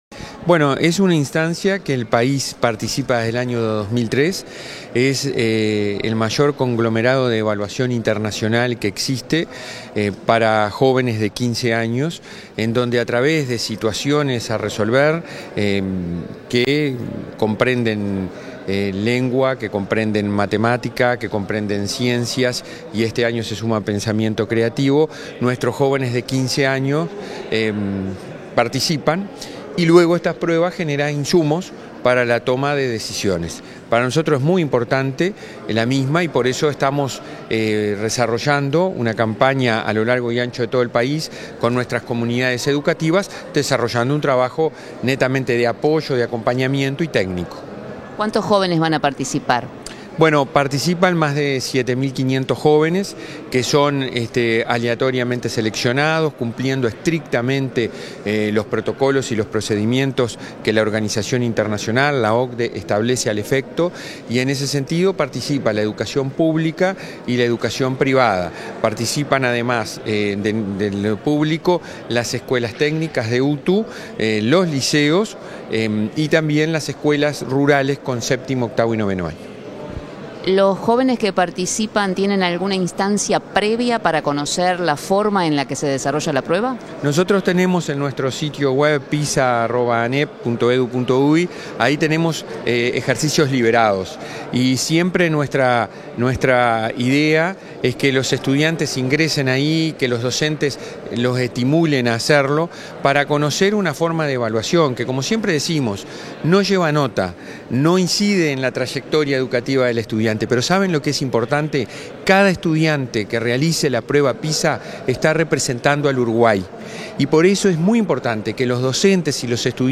Entrevista al presidente del Codicen de la ANEP, Robert Silva